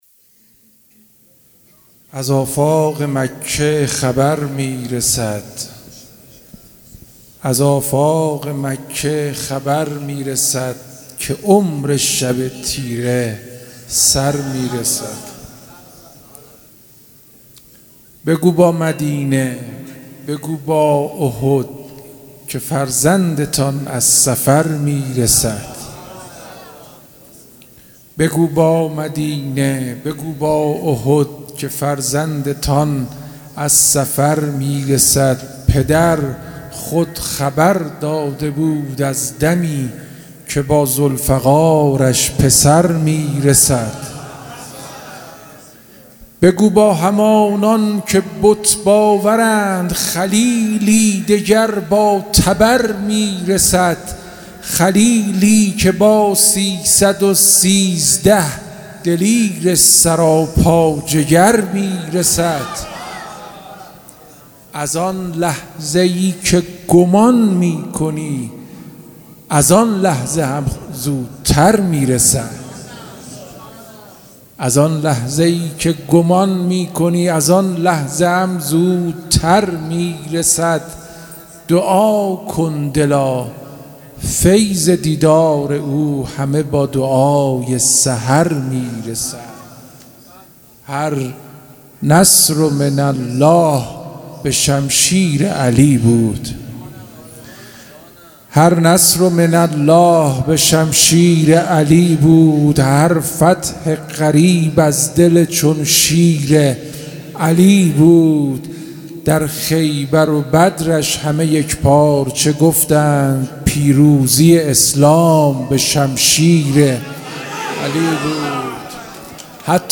مراسم جشن ولادت حضرت صاحب_الزمان (عج)
شعر خوانی
جشن نیمه شعبان